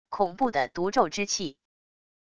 恐怖的毒咒之气wav音频